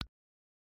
Implement click and level-up sounds, and integrate sound toggle in ResourceDisplay for enhanced user experience.
click2.mp3